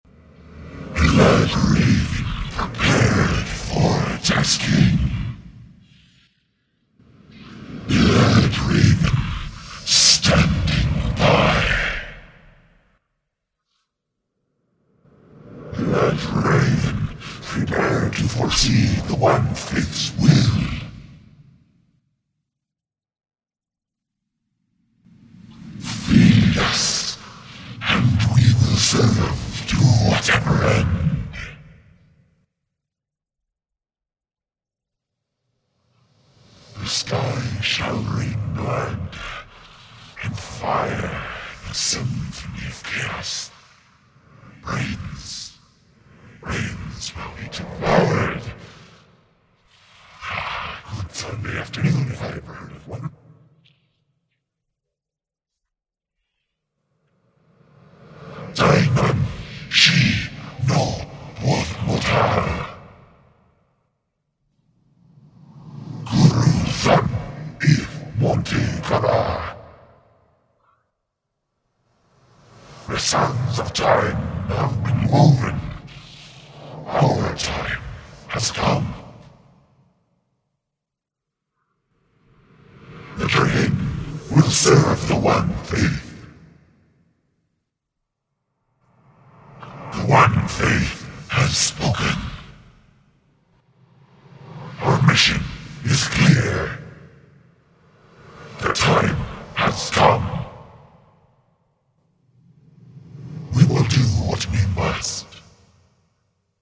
The voice pieces are all my own voice and sound editing.
LTX122responsesfull.mp3